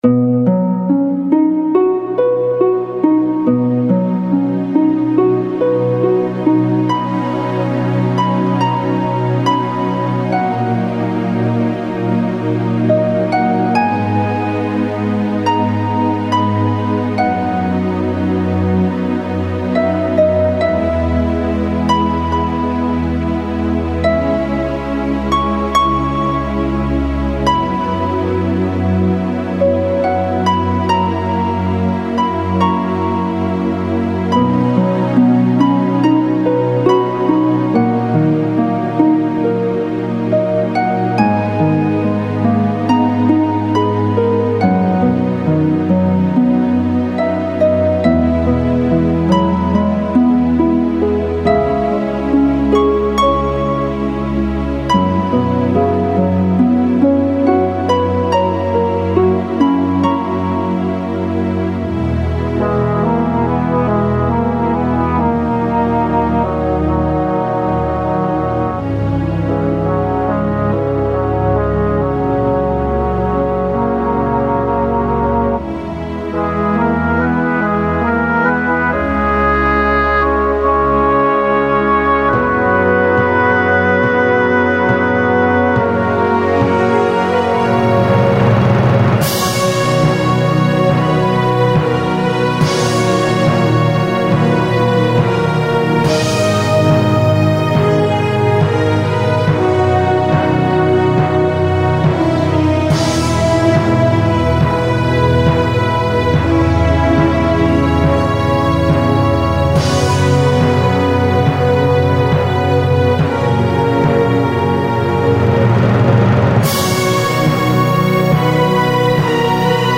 全体的に、平成をイメージするような作りで、打ち込みがメインになります。
壮大で綺麗な曲です。